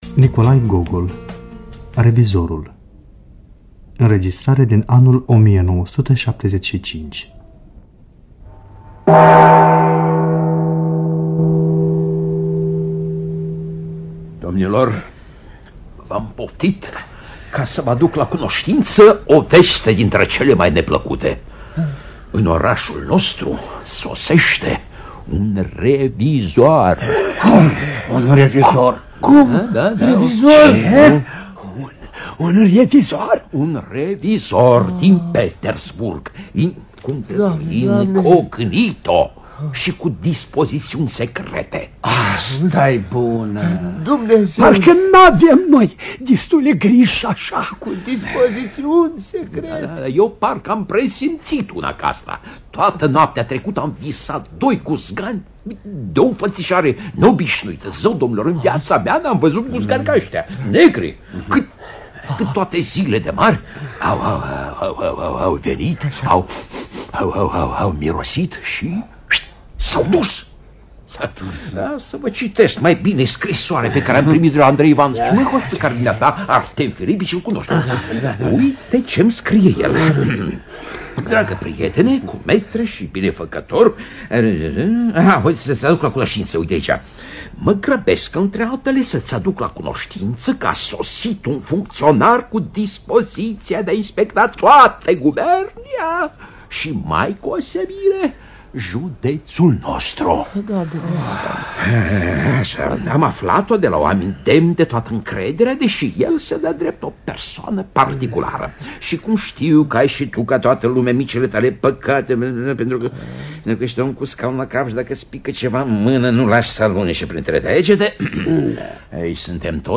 Nikolai Vasilievici Gogol – Revizorul (1975) [TRM] – Teatru Radiofonic Online